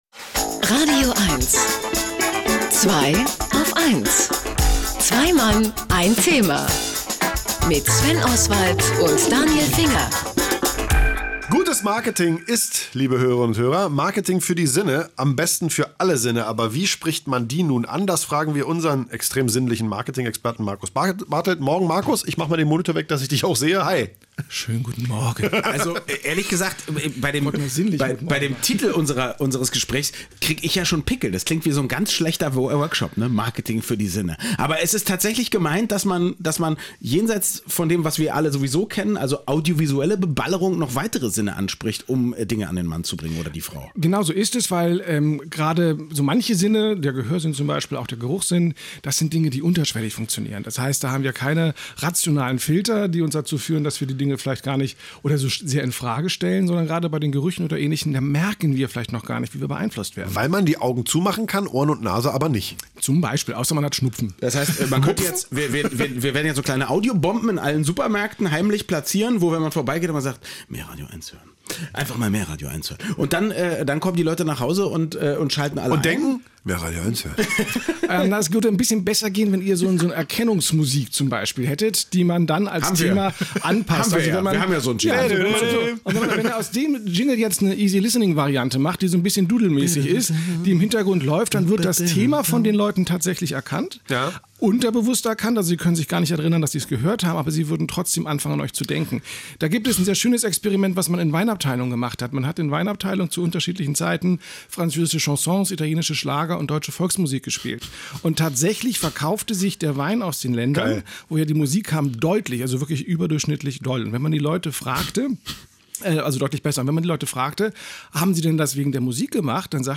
Gerüche gehen direkt ins limbische System und haben daher eine große Wirkung – und werden immer häufiger gezielt im Marketing eingesetzt. Anlass genug, um bei den Herren von „Zweiaufeins“ im „radioeins„-Studio über Marketing für alle Sinne zu sprechen.